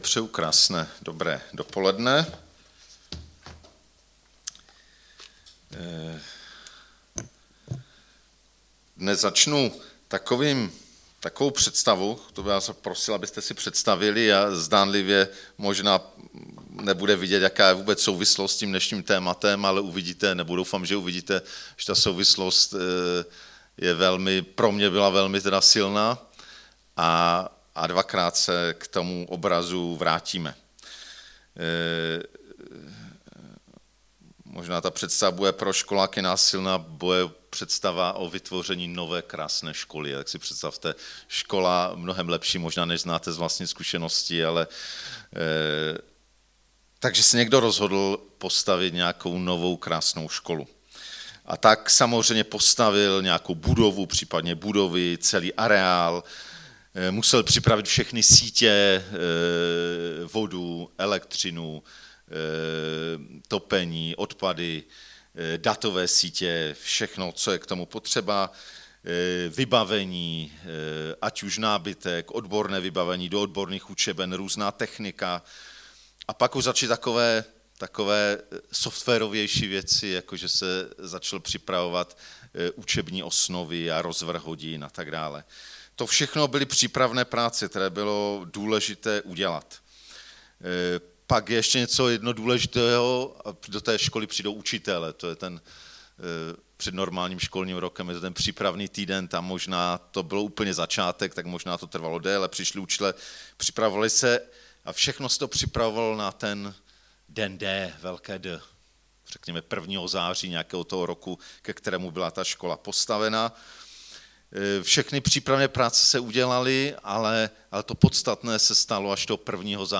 4. díl ze série kázání "Ve světle počátku", Gn 2,1-3
Kategorie: nedělní bohoslužby